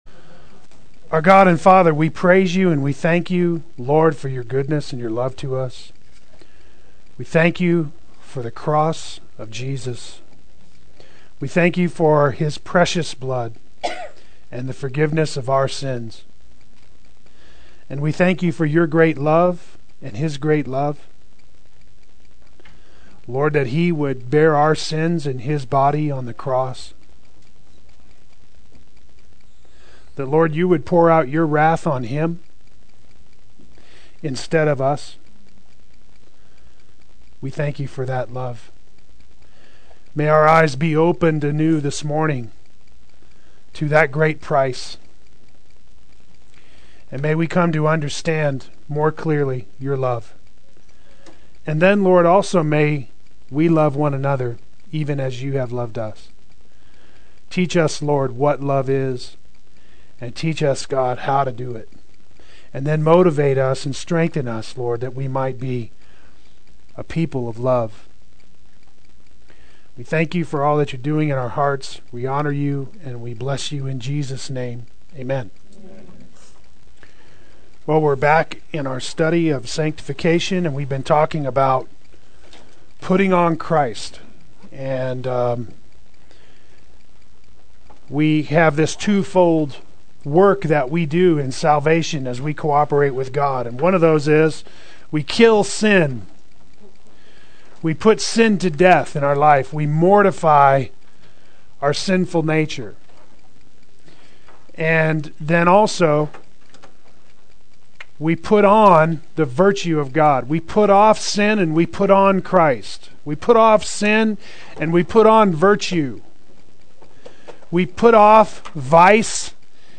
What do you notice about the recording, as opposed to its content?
Love Defined-Part 3 Adult Sunday School